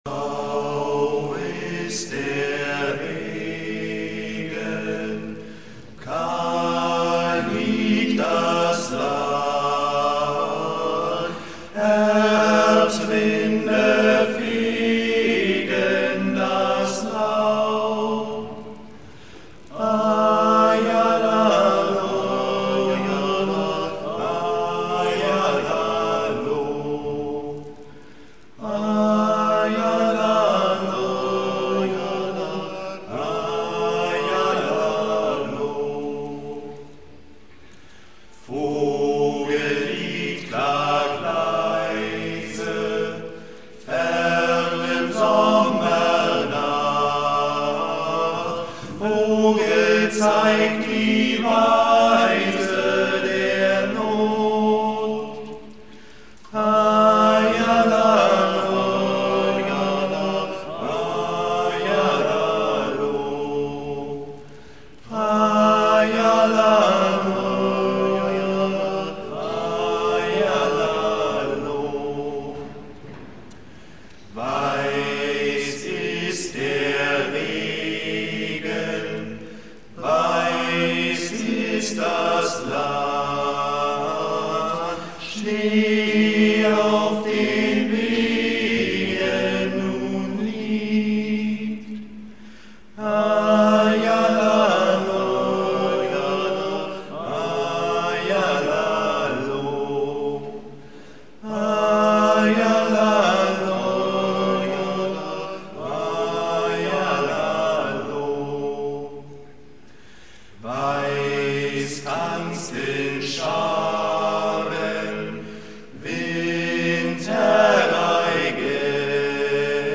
Berliner Singewettstreit 2000
Einer unserer ersten Auftritte